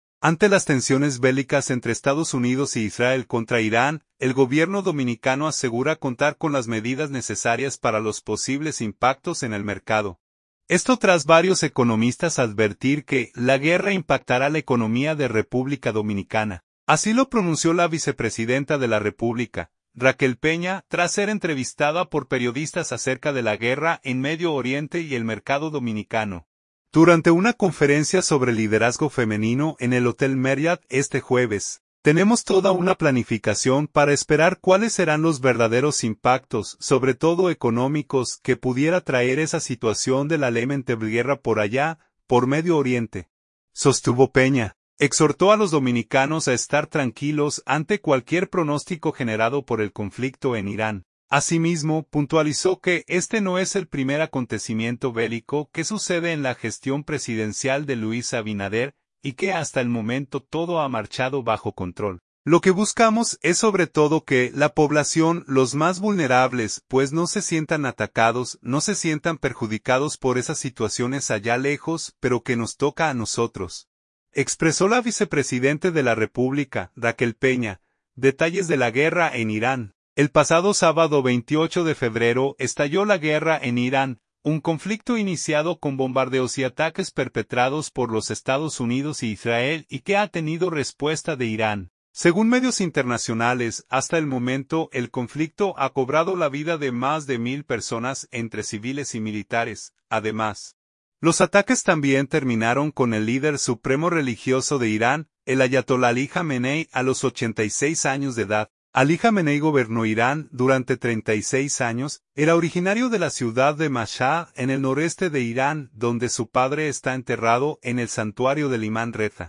Así lo pronunció la vicepresidenta de la República, Raquel Peña, tras ser entrevistada por periodistas acerca de la guerra en Medio Oriente y el mercado dominicano, durante una conferencia sobre liderazgo femenino en el Hotel Marriott, este jueves